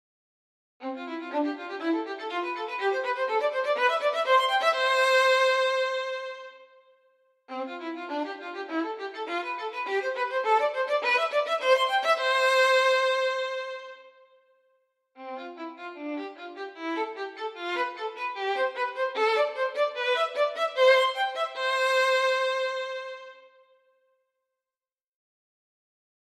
Mostly they sound soft in their lower levels .
Above of level 88 they have a powerfull sound.